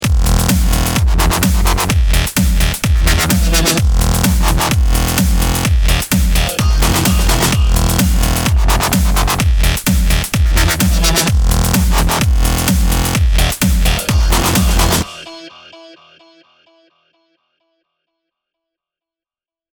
That is, we actually want some of that infamous ‘pumping’ effect, because we feel that that would fit the heavy bass sounds perfectly.
You will notice that it not even sounds more consistent, but also fuller due to the saturation which is introduced by the plugin.
Big-Electro-House-Basses-with-GTS-39-WET.mp3